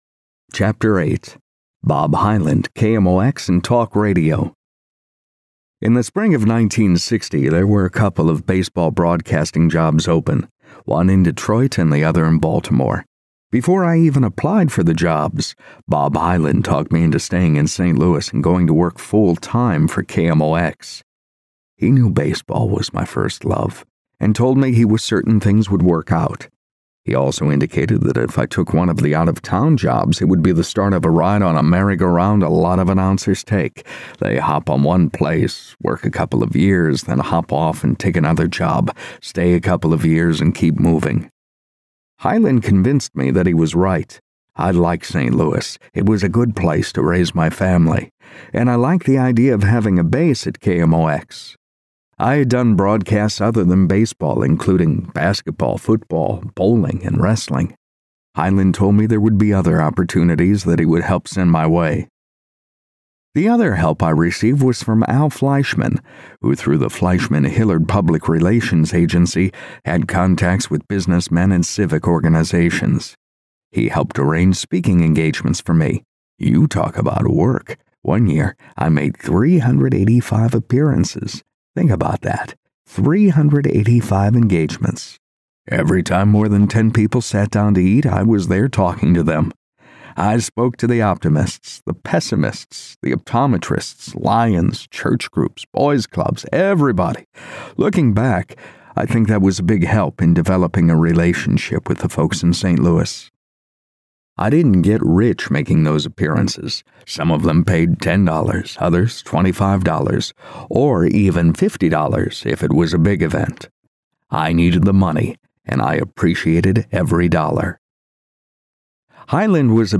Having a blast narrating the autobiography of Jack Buck, my favorite disc jockey of all time.